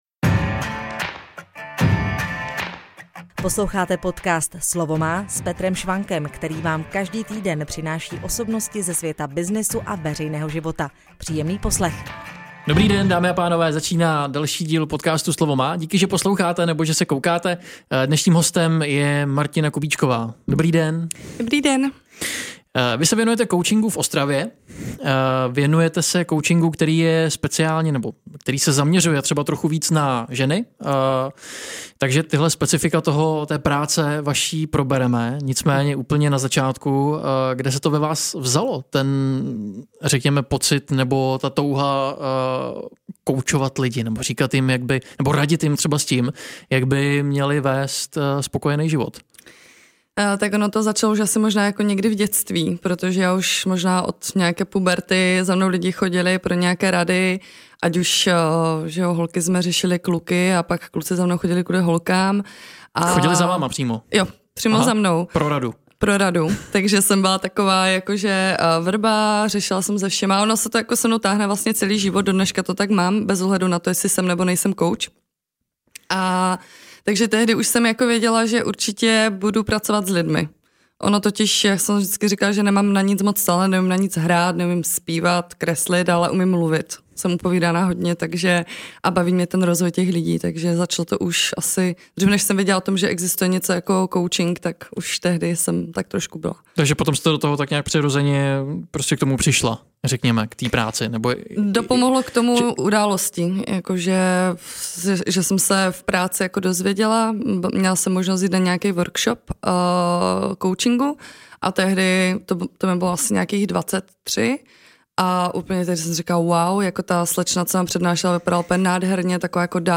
V rozhovoru se dozvíte, jak těmto situacím nejlépe předcházet.